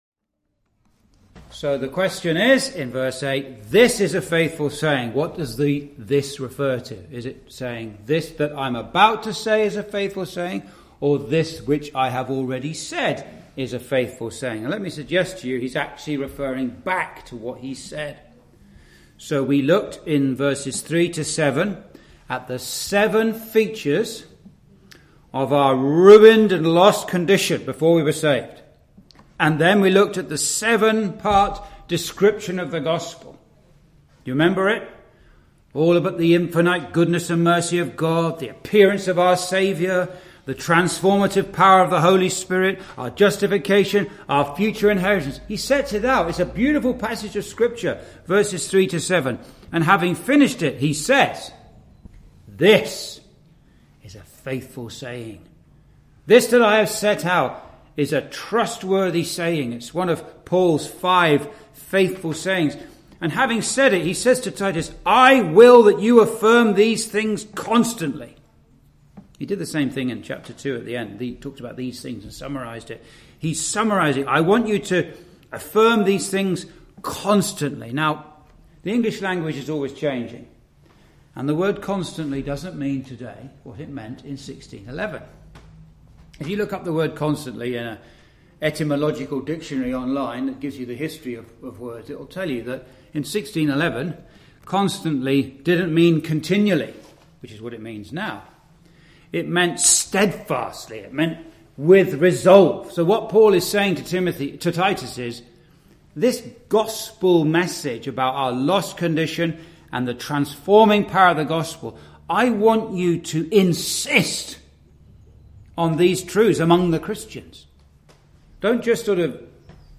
(Message preached in Chalfont St Peter Gospel Hall, 2022)
Verse by Verse Exposition